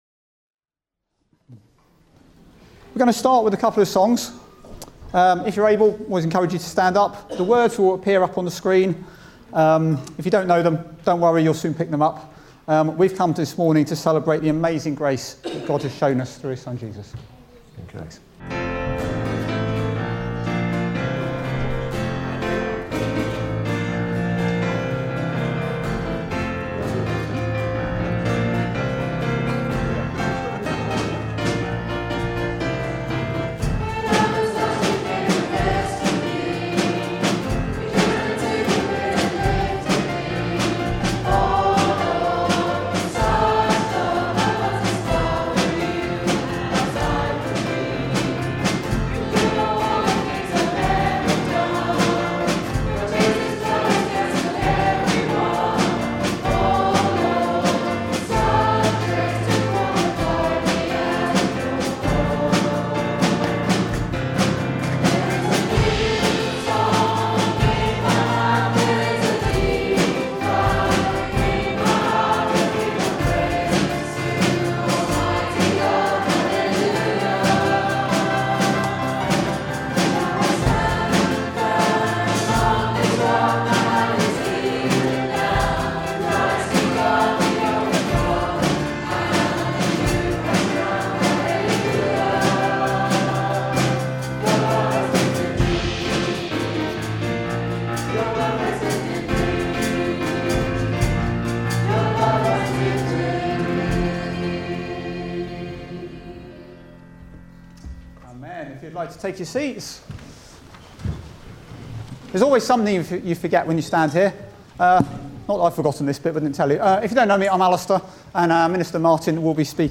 26 October 2025 – Morning Baptismal Service
Service Type: Morning Service